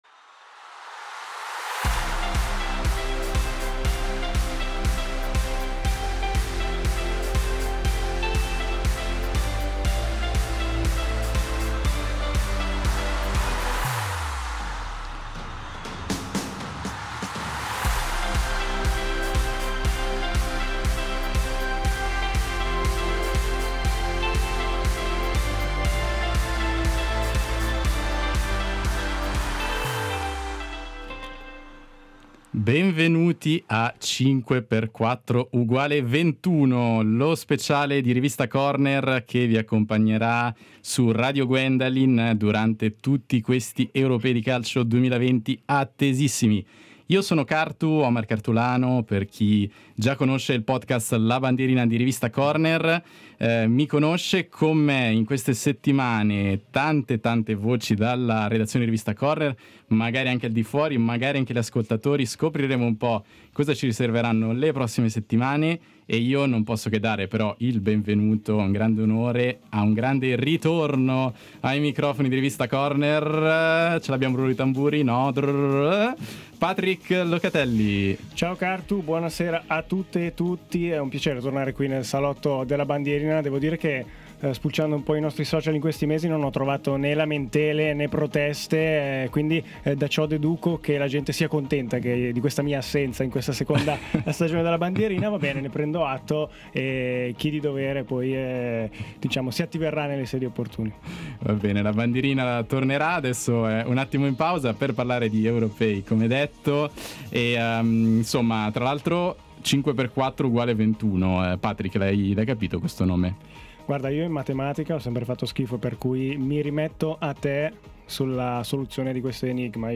in diretta dallo studio di Radio Gwen hanno fatto il punto anche sulla Svizzera… ce la farà a scrivere la storia?